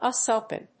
アクセントÚS Ópen